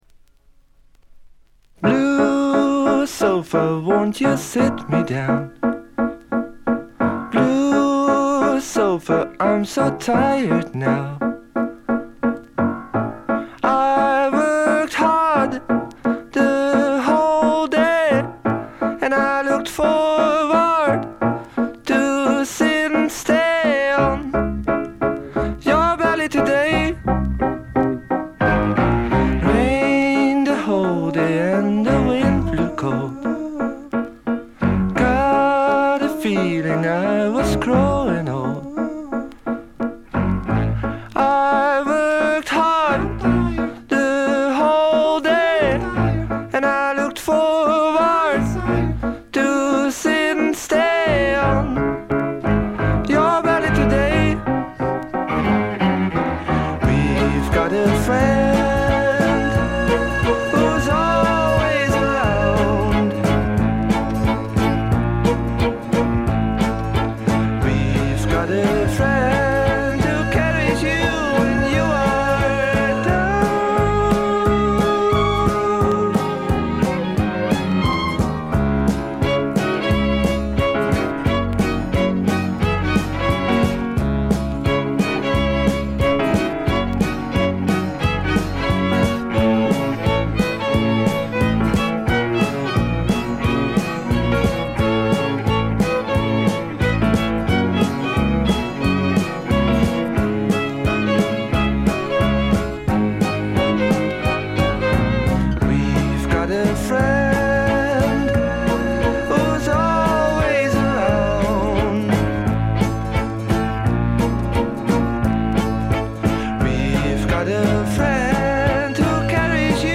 ざっと全編試聴しました。バックグラウンドノイズ、チリプチやや多め大きめ。
全体はチェンバー・ロック風な雰囲気ですが、フォークロックとか哀愁の英国ポップ風味が濃厚ですね。
試聴曲は現品からの取り込み音源です。